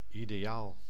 Ääntäminen
US : IPA : [aɪ.ˈdi.əl]